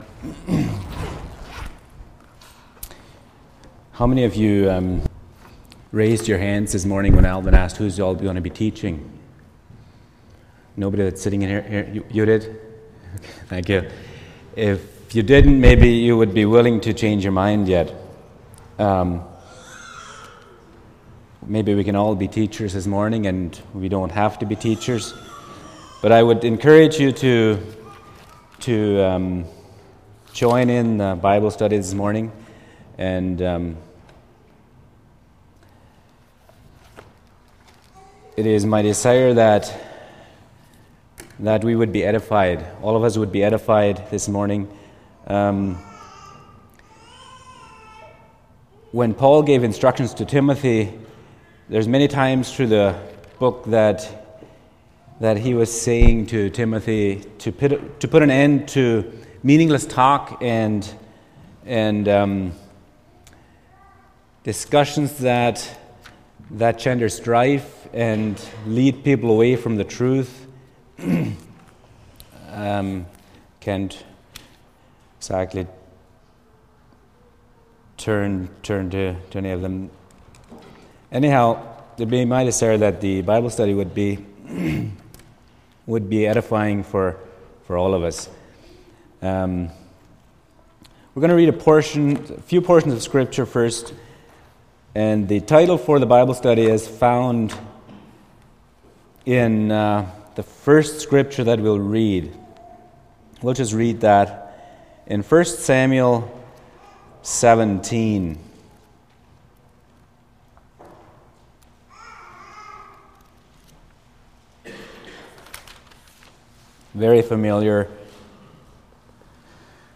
Sunday Morning Bible Study Service Type: Sunday Morning %todo_render% « Abraham’s Faith